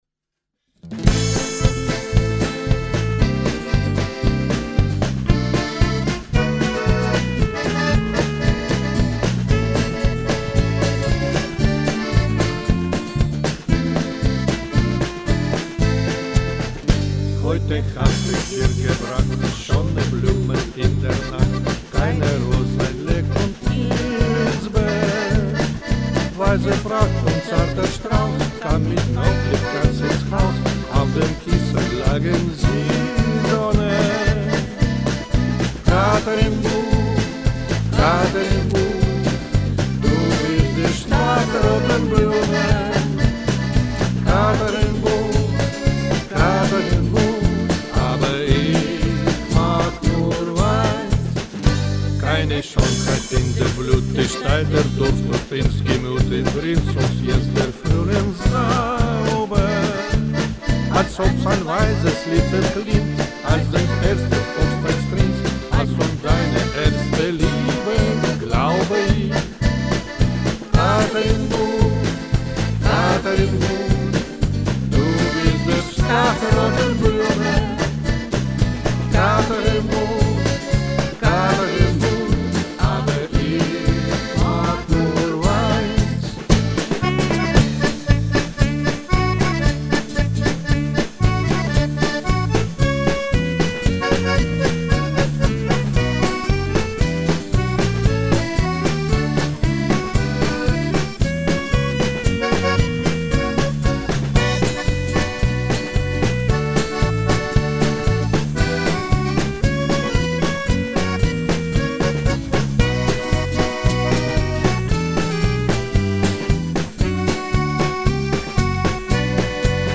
Поют и играют у нас любители.
Стараемся петь на голоса, чтобы было красиво.